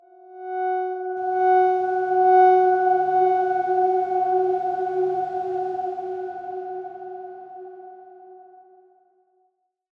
X_Darkswarm-F#4-pp.wav